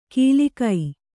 ♪ kīlikai